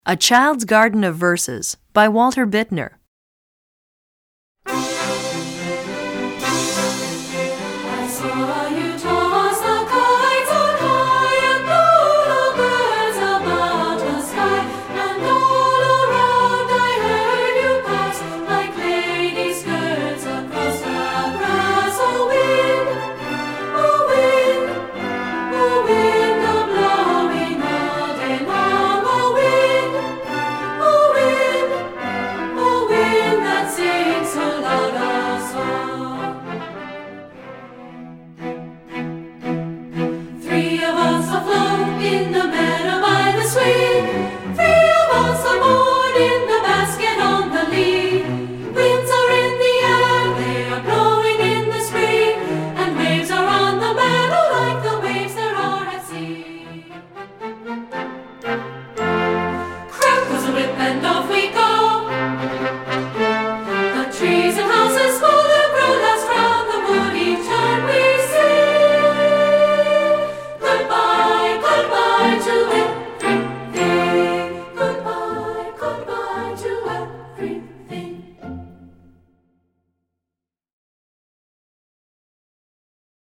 Voicing: VoiceTrax CD